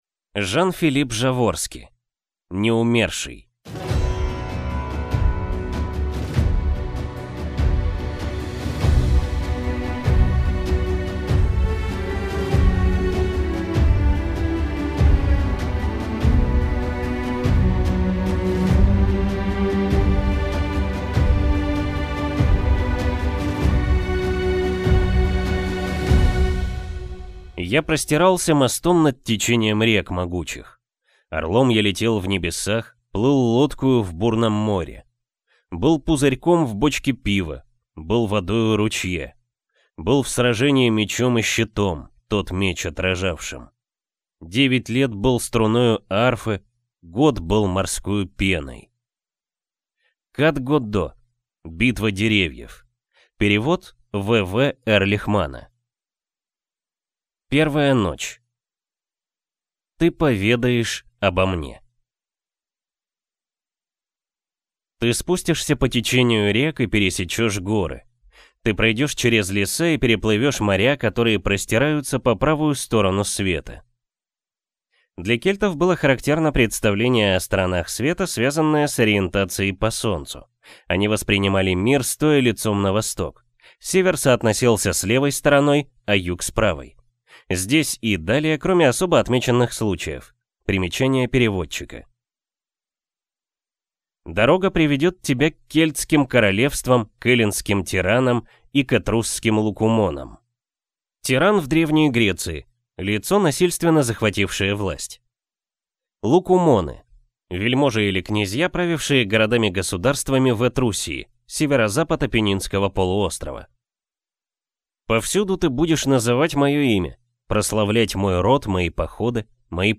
Аудиокнига Неумерший | Библиотека аудиокниг